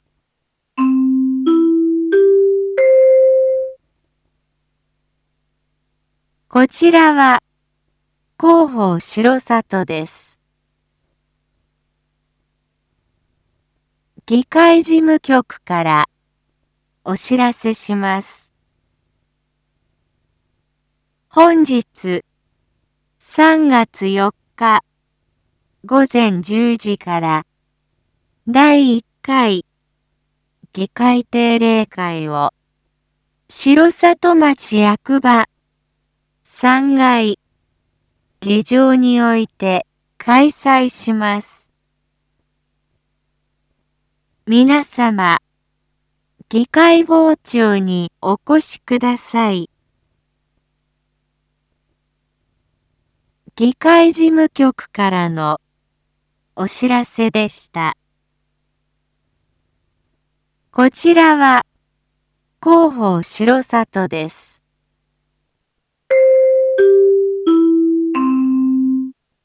Back Home 一般放送情報 音声放送 再生 一般放送情報 登録日時：2025-03-04 07:01:11 タイトル：議会定例会（２） インフォメーション：こちらは広報しろさとです。